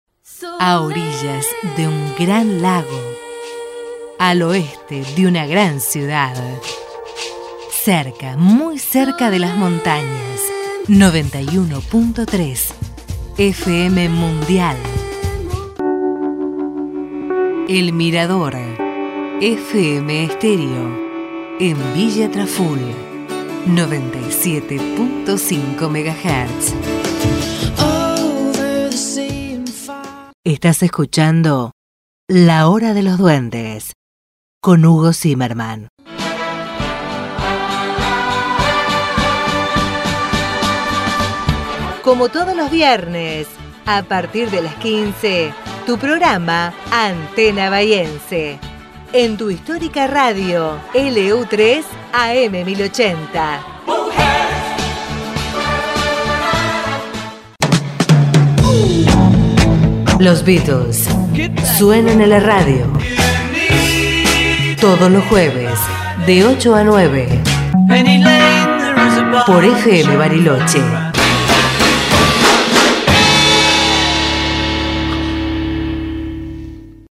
Artística para programas y radios
Armado de piezas a pedido: apertura, cierre, separadores, promos.